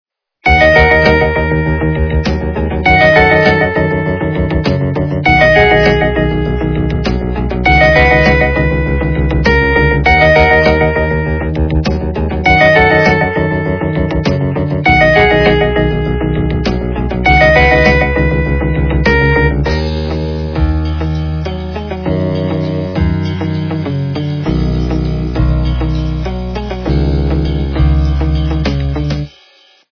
- рок, металл
качество понижено и присутствуют гудки.